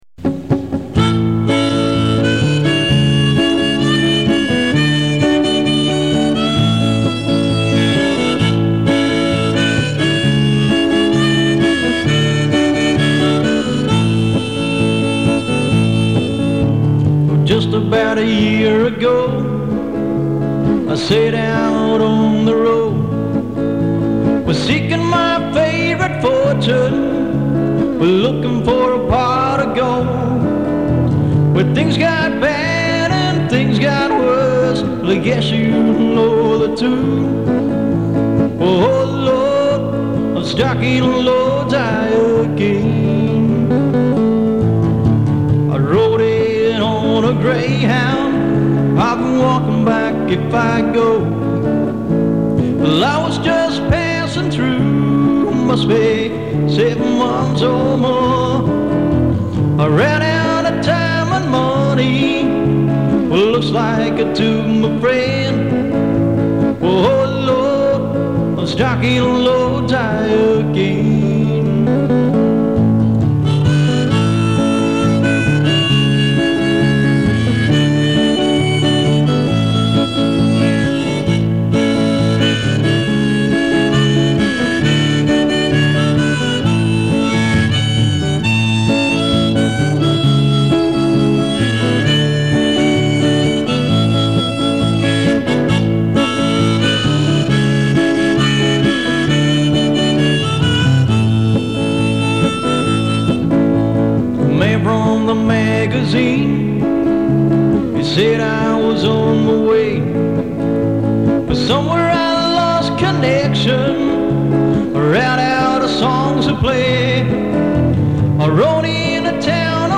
These are cassette recordings as far back as 1987.
So the quality may not be top-notch, but reasonable.